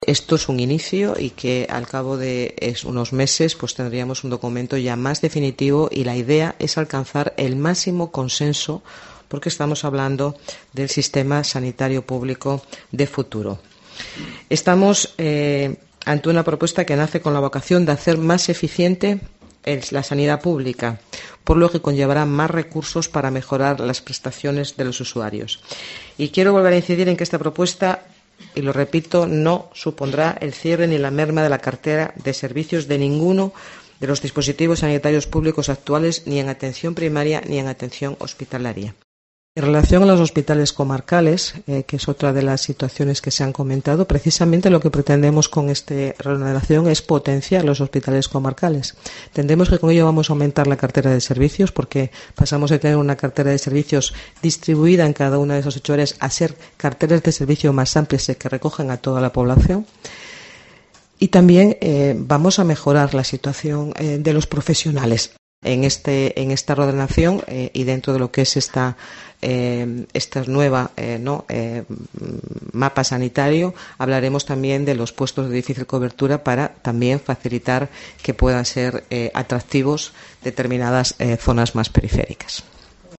Concepción Saavedra explica la propuesta de nuevo mapa sanitario